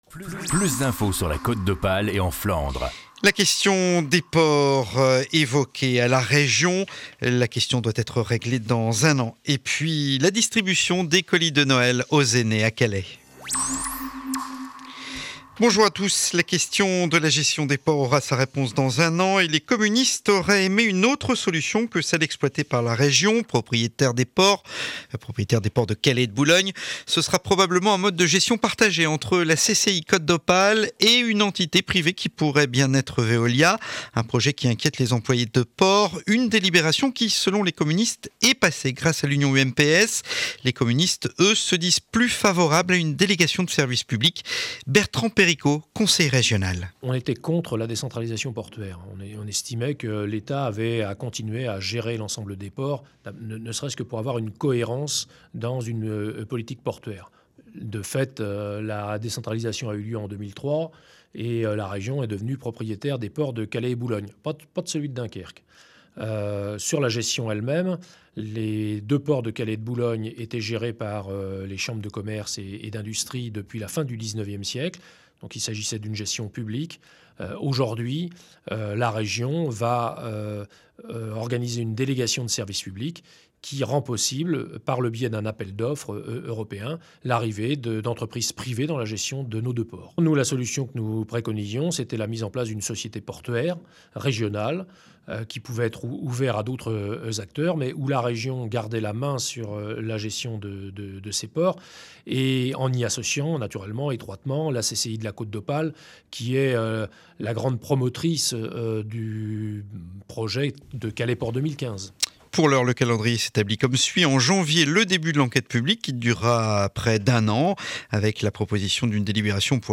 Journal de 7h30 du jeudi 8 décembre édition de Calais.